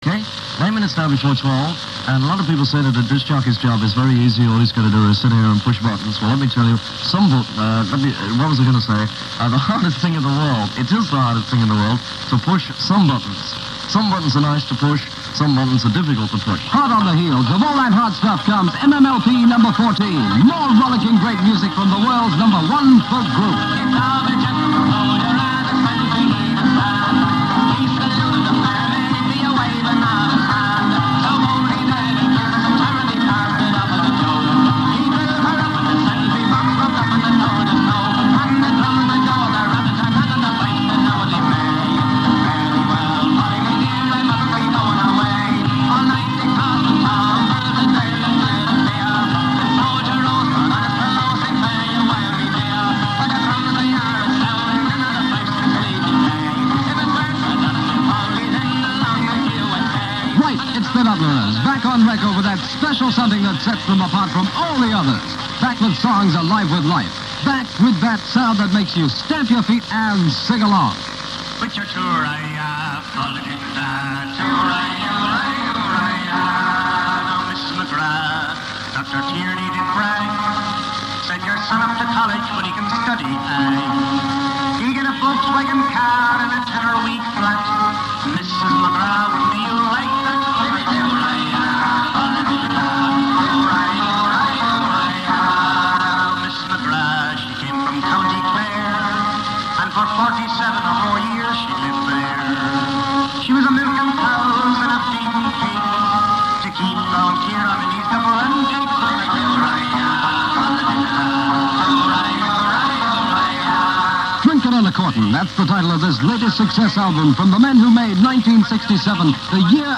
Johnnie Walker being critical of a rather long - but typical -  Major Minor promo
Caroline South - Johnnie Walker critical of Major Minor promo.mp3